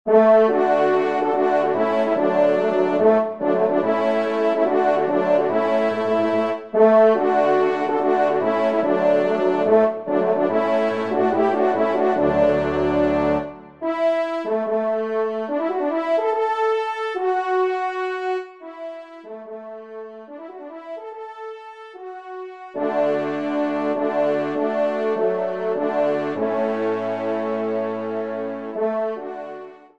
ENSEMBLE